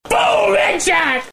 Boom Headshot Sound Effect Free Download